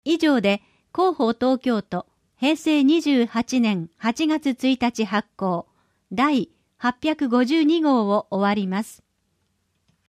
「広報東京都音声版」は、視覚に障害のある方を対象に「広報東京都」の記事を再編集し、音声にしたものです。
終わりアナウンス（MP3：80KB） 10秒